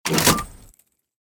into_cannon.ogg